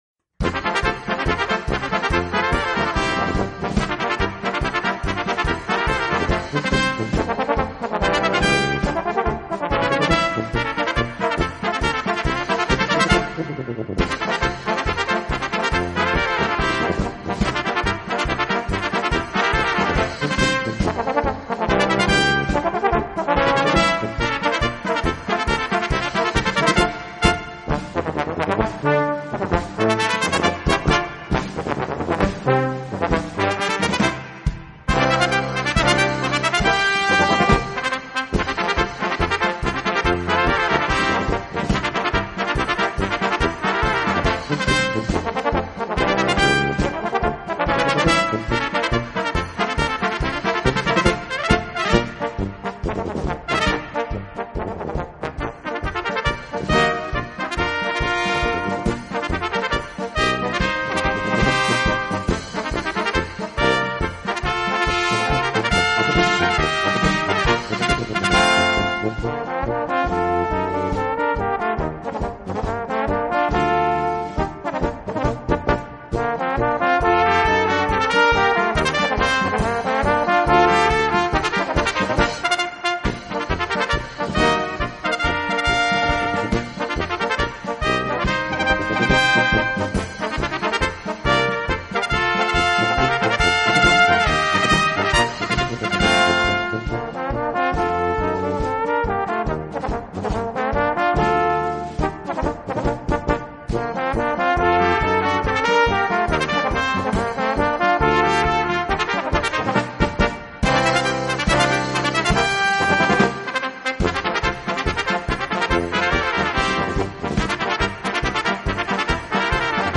Gattung: Polka
Besetzung: Kleine Blasmusik-Besetzung